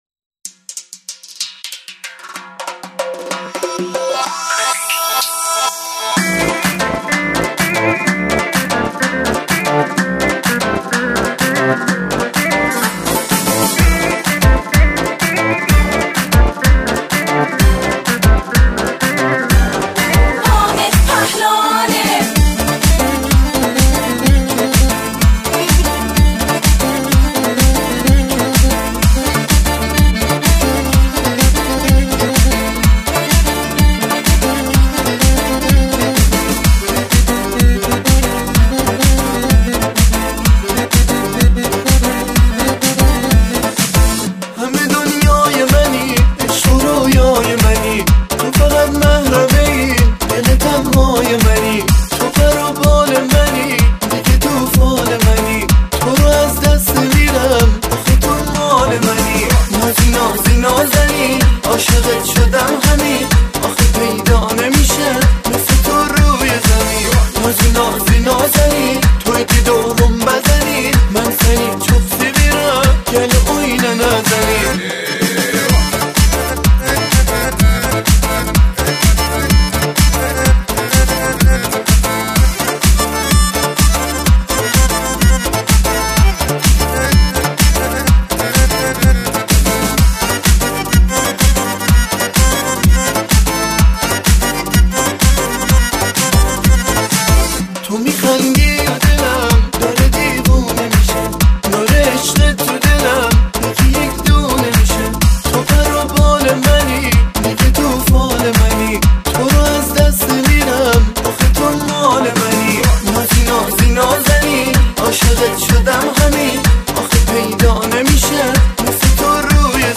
آهنگ ارکستی و شاد تالار عروسی